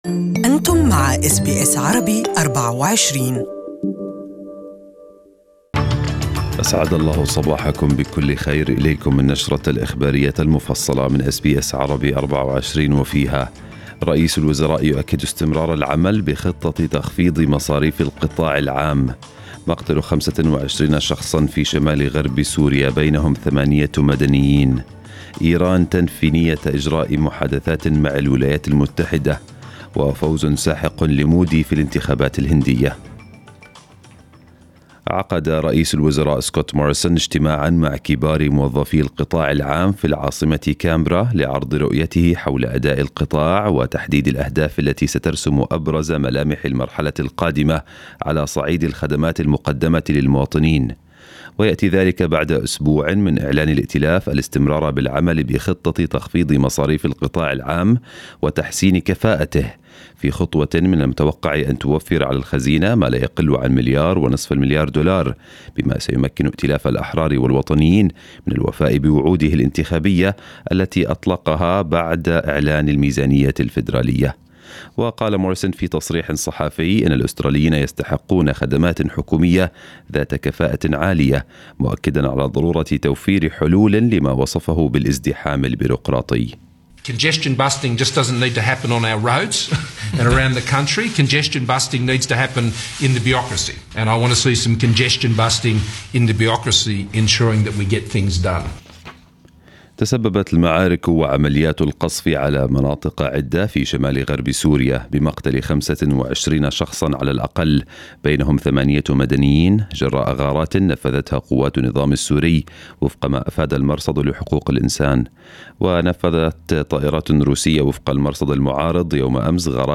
Morning Arabic news bulletin 24/5/2019